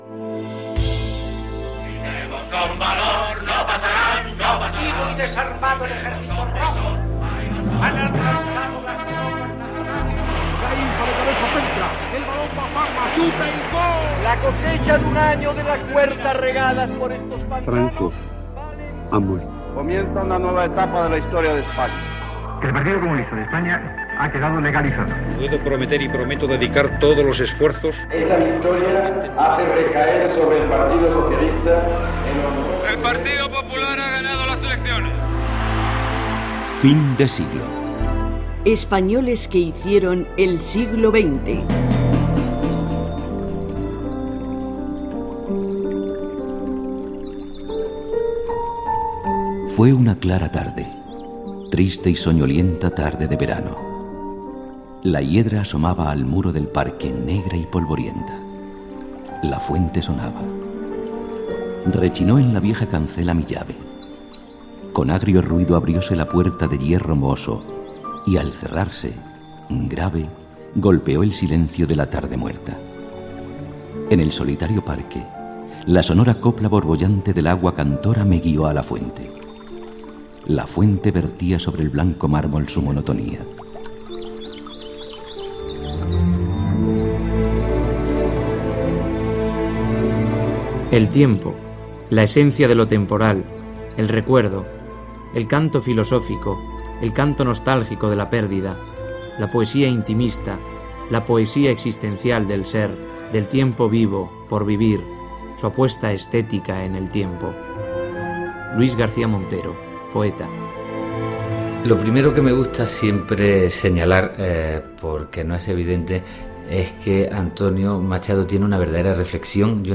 Careta del programa.
Divulgació